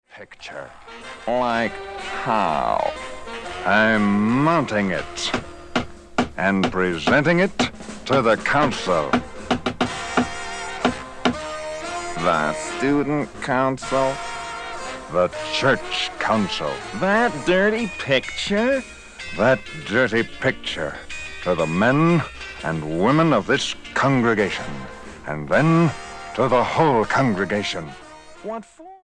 STYLE: Jesus Music
baritone speaking voice
fuzz guitars, groovy sounds and a sprinkling of avant garde